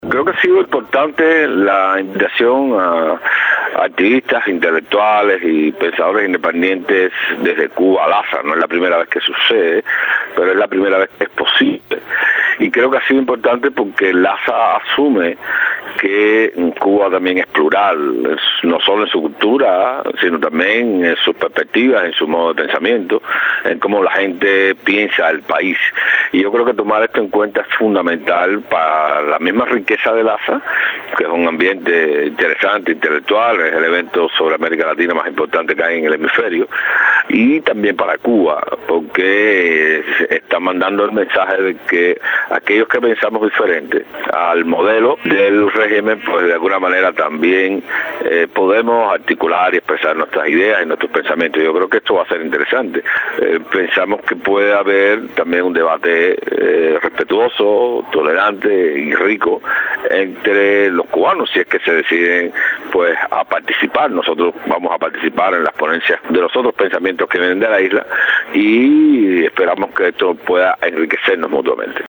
De visita en los Estados Unidos el también líder del partido Arco Progresista en Cuba Cuesta Morúa habló con Radio Martí sobre la importancia de participar en el evento.